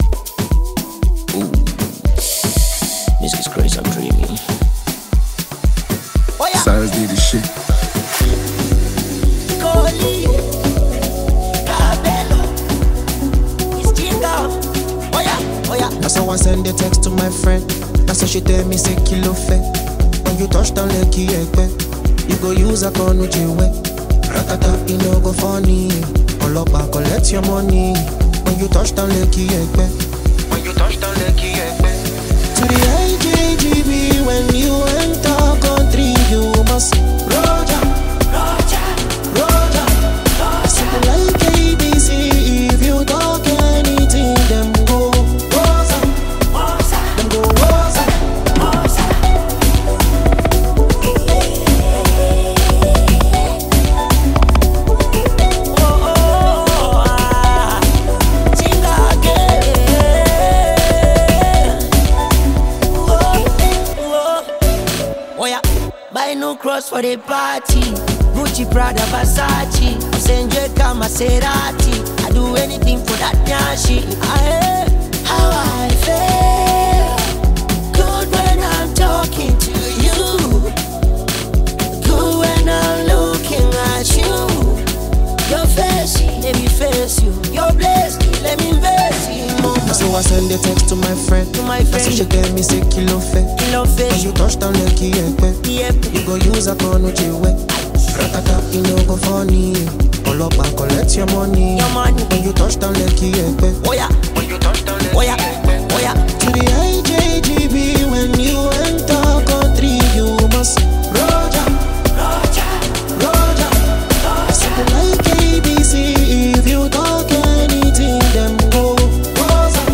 a more vulnerable and melodic side